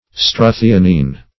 Struthionine \Stru`thi*o"nine\